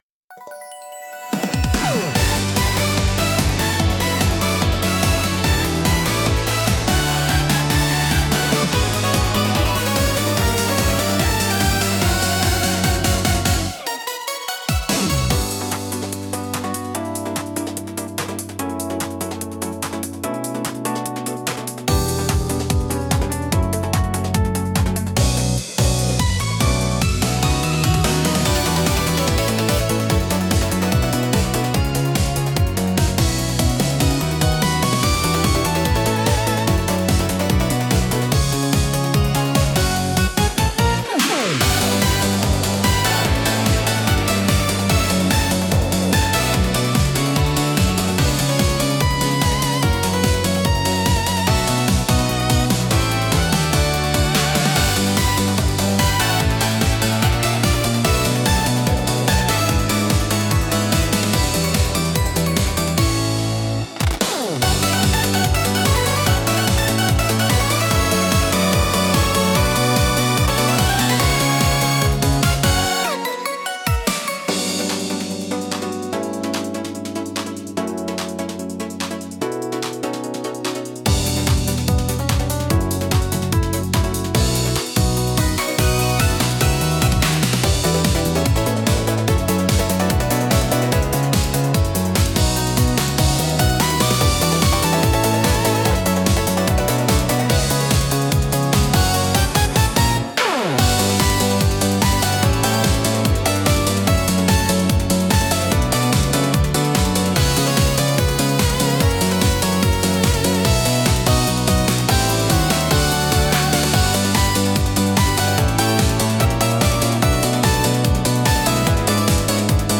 聴く人の気分を高め、緊張と興奮を引き立てるダイナミックなジャンルです。